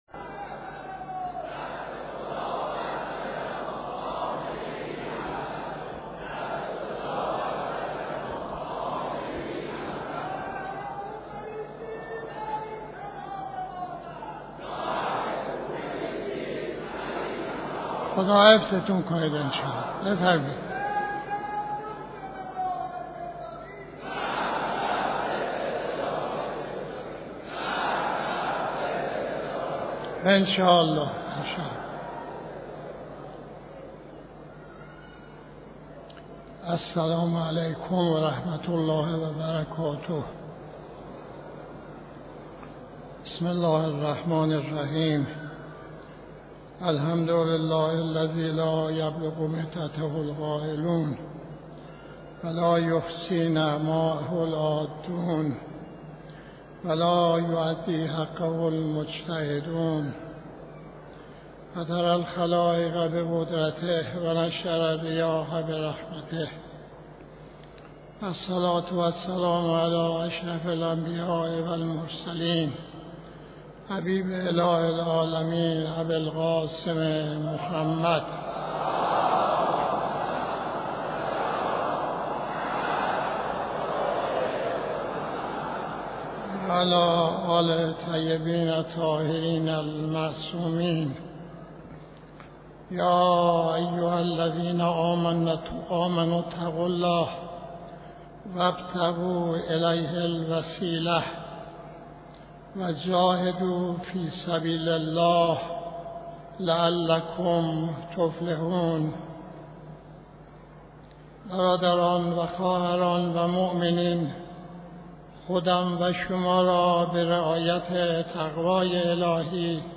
خطبه نماز جمعه 05-03-91